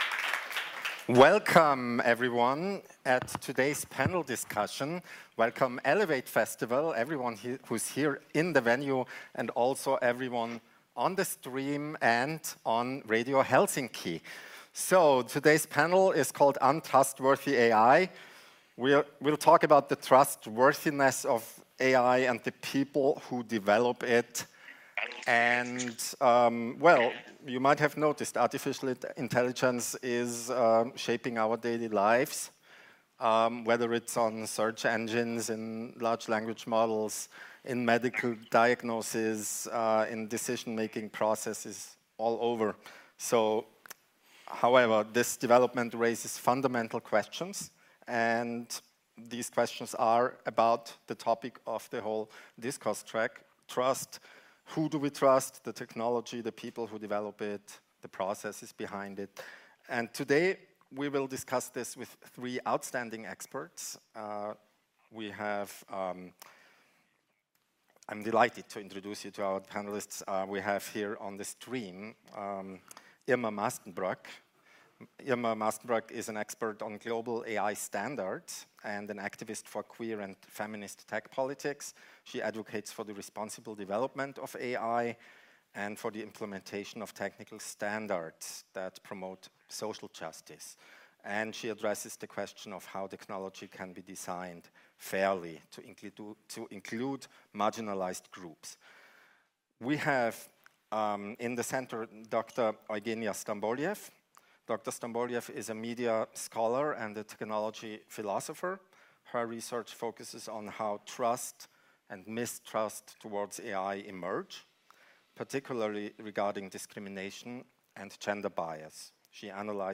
Es geht darum, die Infrastruktur und Regeln zu hinterfragen, die diese Systeme formen. Dieses Panel versammelt Expert:innen, die sich dem unkritischen Vertrauen in KI und den dahinter stehenden Machtstrukturen entgegenstellen.